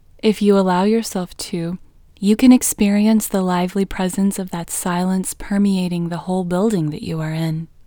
WHOLENESS English Female 8